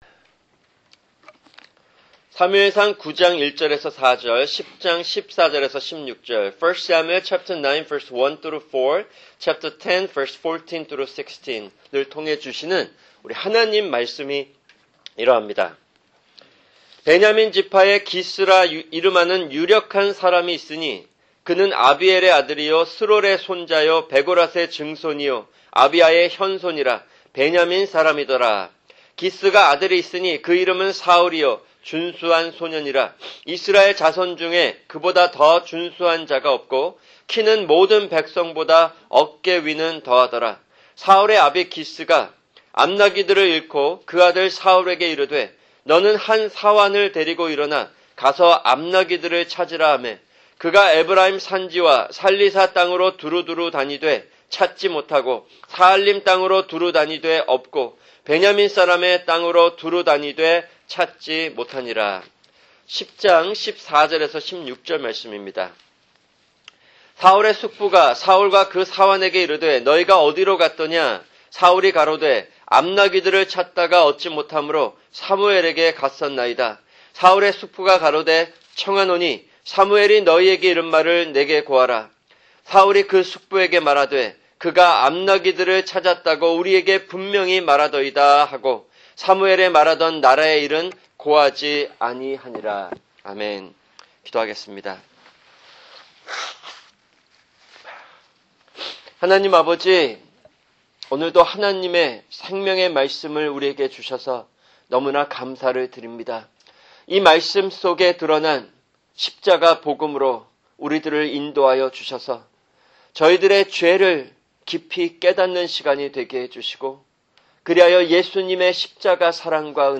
[주일 설교] 사무엘상(26) 9:1-10:16(2)